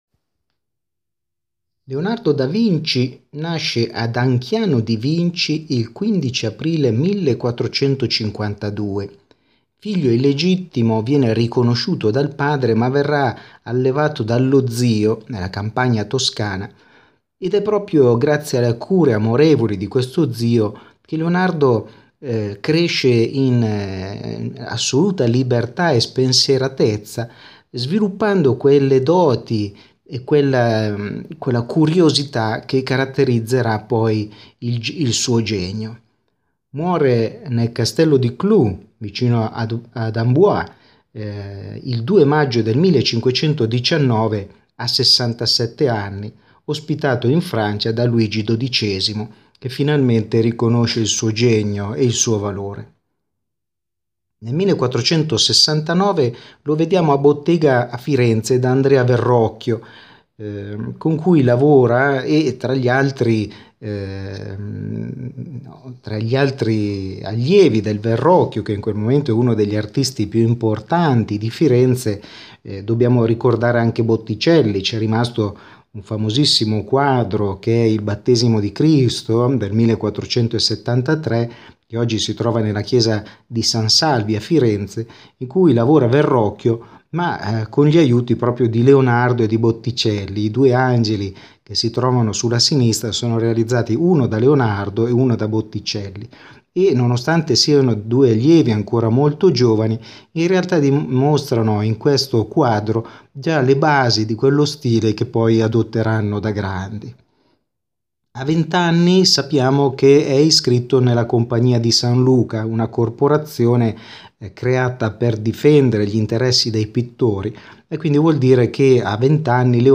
Ascolta la lezione audio dedicata a Leonardo da Vinci Adorazione dei Magi.